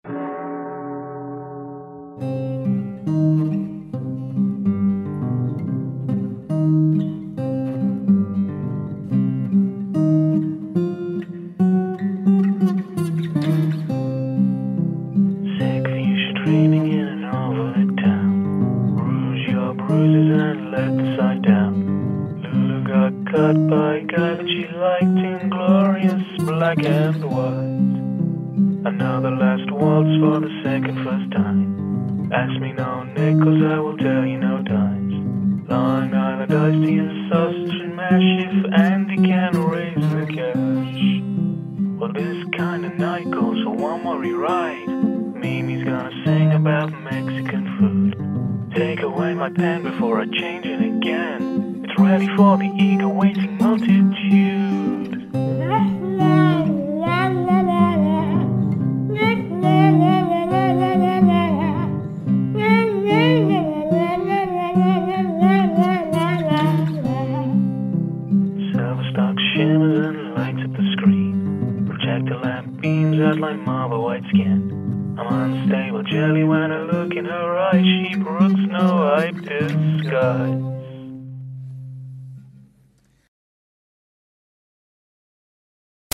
gob-trumpet